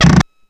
Index of /90_sSampleCDs/E-MU Producer Series Vol. 3 – Hollywood Sound Effects/Miscellaneous/WoodscrewSqueaks
WOOD SQUEA01.wav